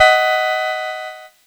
Cheese Chord 29-B4.wav